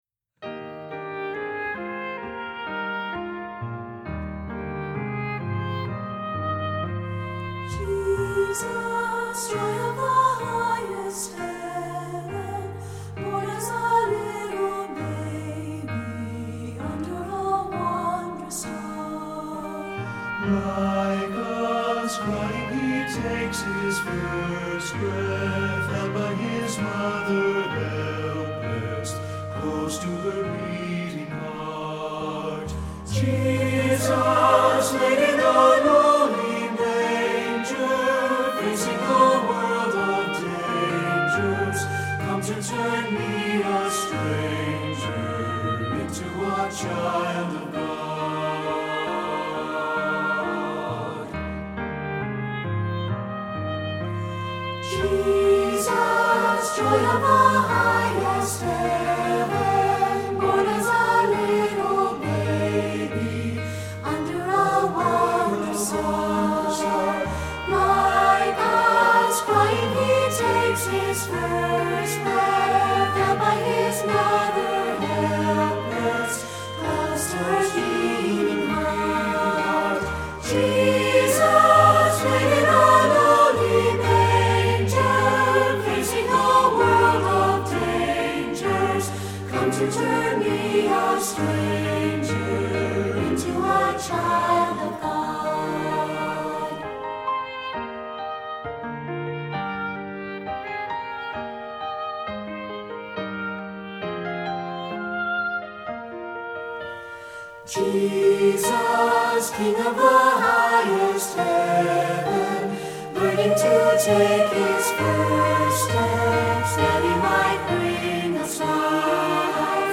Voicing: SATB and Oboe